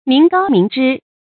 民膏民脂 注音： ㄇㄧㄣˊ ㄍㄠ ㄇㄧㄣˊ ㄓㄧ 讀音讀法： 意思解釋： 膏、脂：油脂。比喻人民用血汗創造的財富 出處典故： 宋 張唐英《蜀壽杌》下卷：「爾俸爾祿， 民膏民脂 。